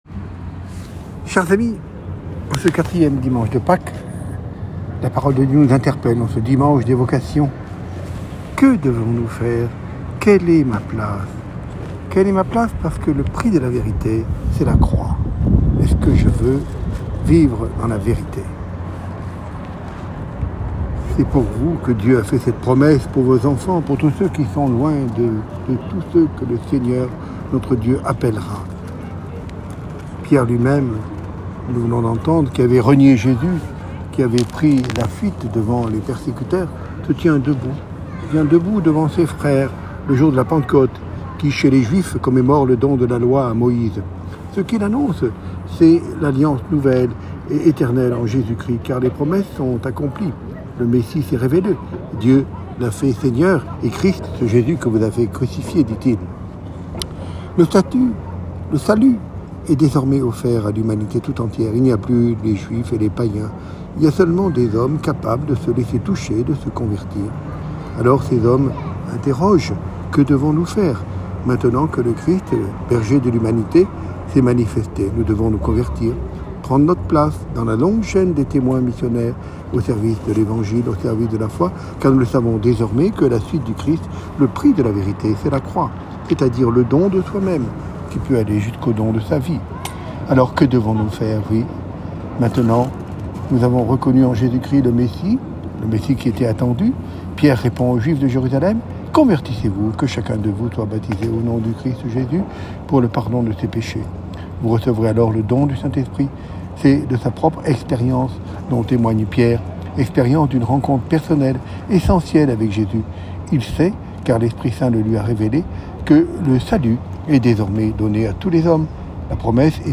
Solennité de Notre-Dame d’Afrique
Ecouter l’homélie de Mgr Georges Colomb, Directeur national des OPM